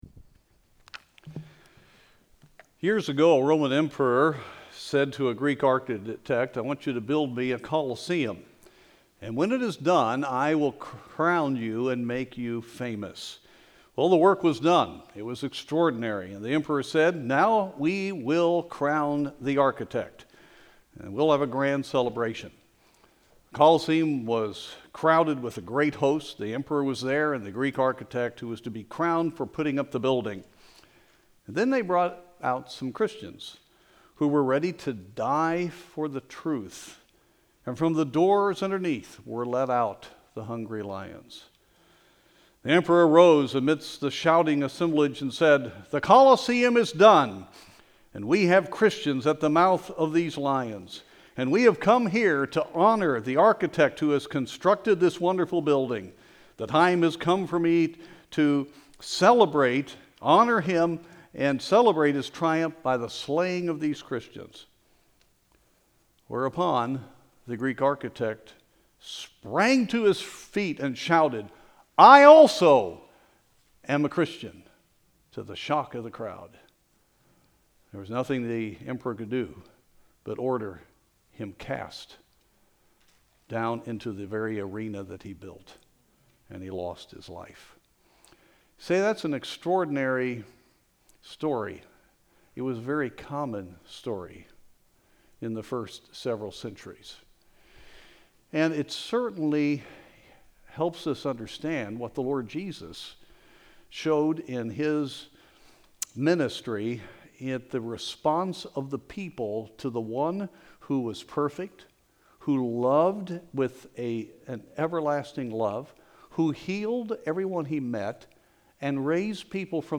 Spring 2020 Sunday Morning Sermon Series